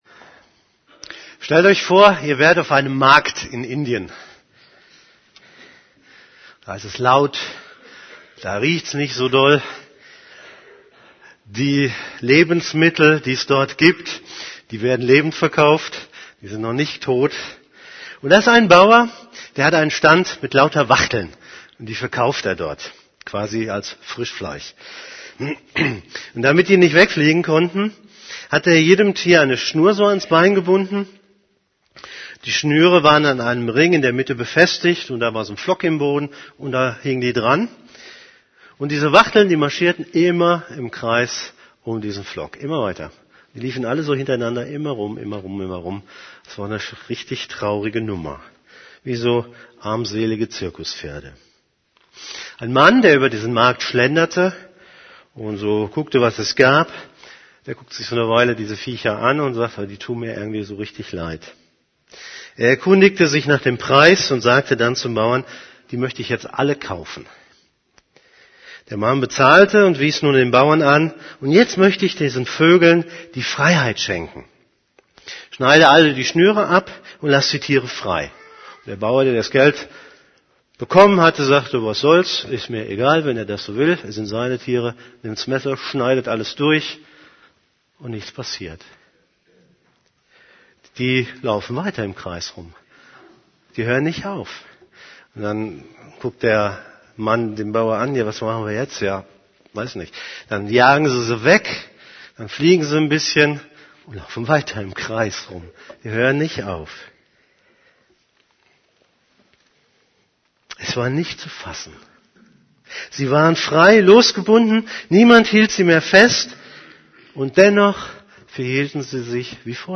> Übersicht Predigten Wir sind zur Freiheit berufen Predigt vom 22.